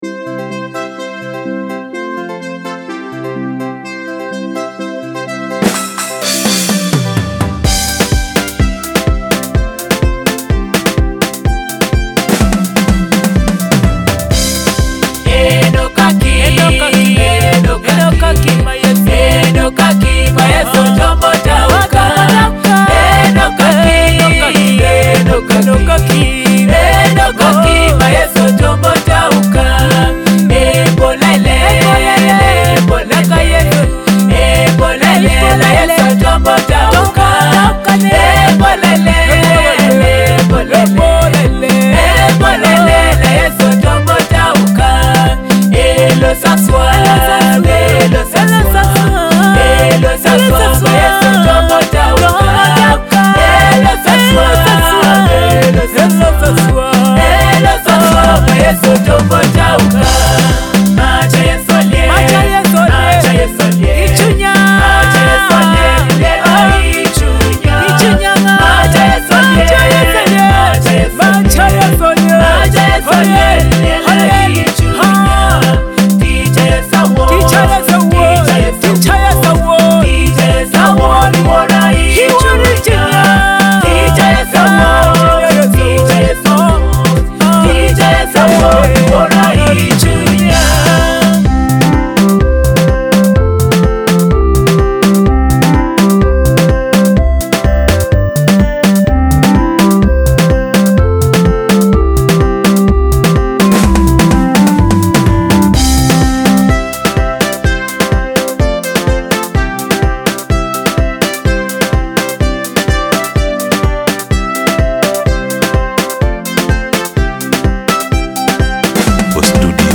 vibrant Teso gospel melodies
soul-stirring melody for joyful listening